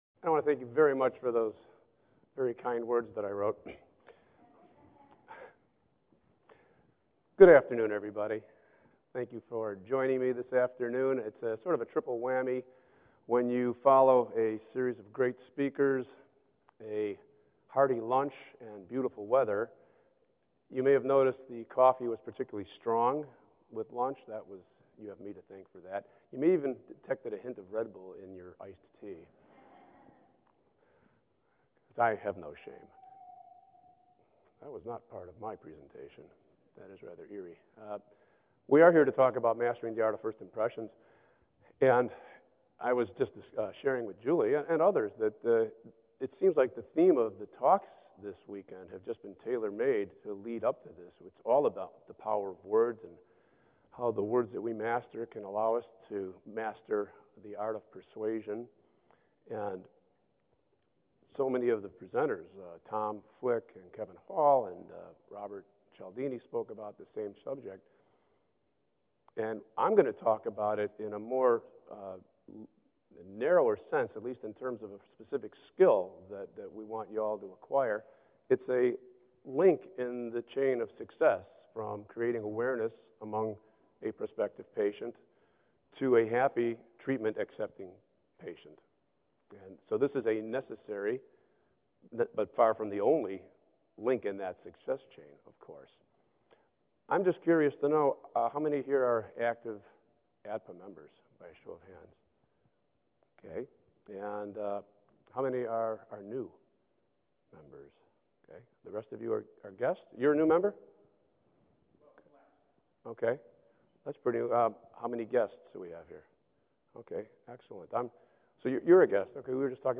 at the Annual Meeting of The American Academy of Dental Practice Administration in Indian Wells, CA